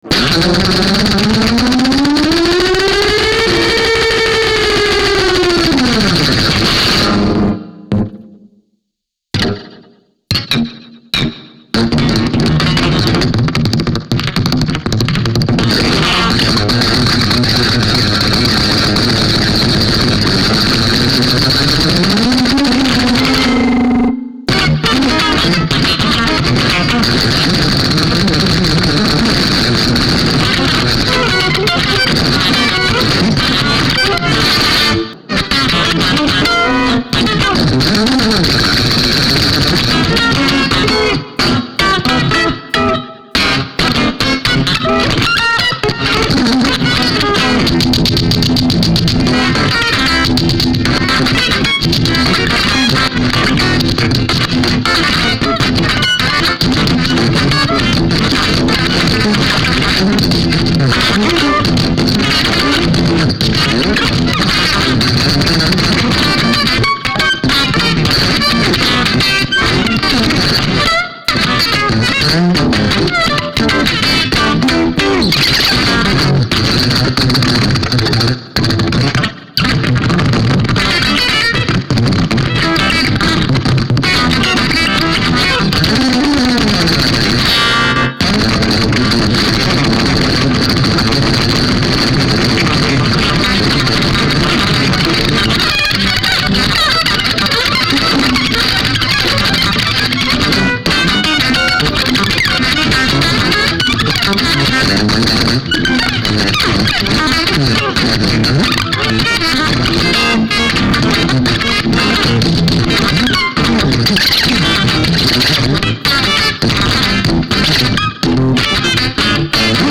これは、2012年8月25日（土）におこなわれた演奏会の実況録音です。
録音は完全にステレオ・マイクによる収録のみでおこなわれ、ライン信号は一切使用されていません。
その夜、演奏されたままの音で、編集時の加工は音質補正のみです。
もちろんオーバーダビングもありません。